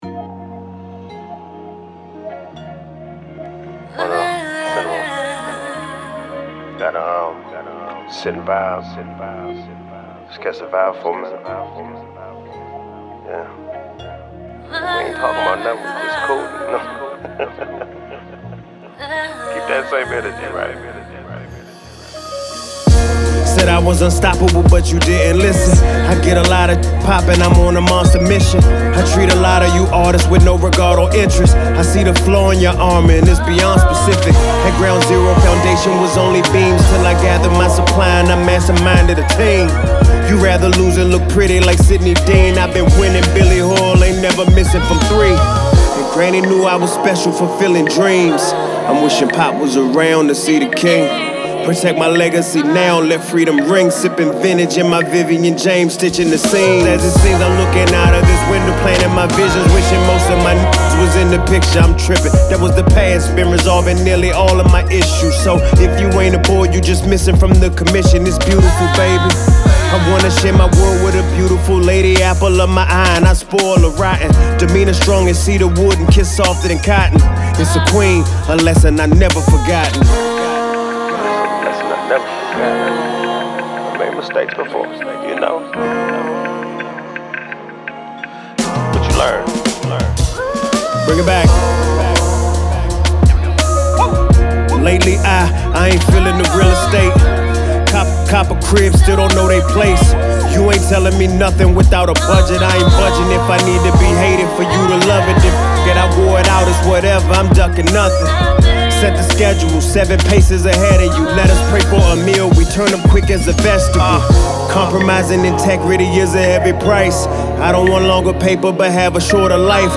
Hiphop
a soulful journey of introspection